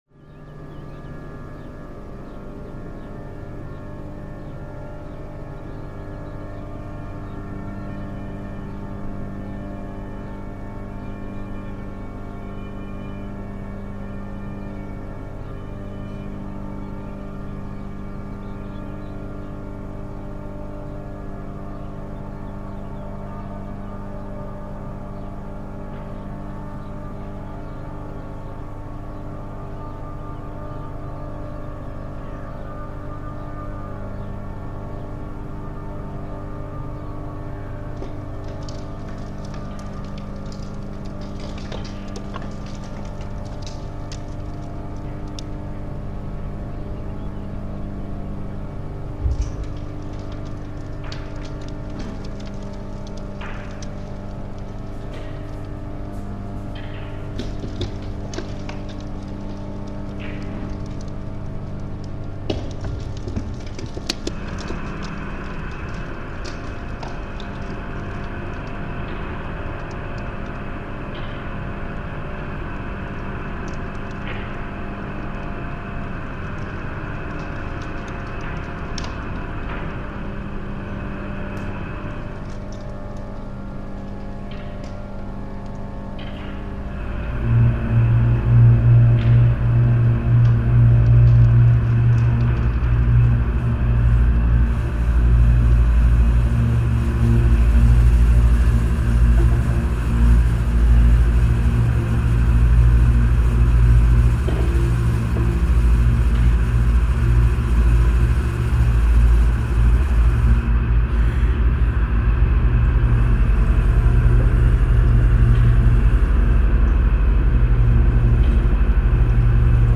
acousmatic sound collages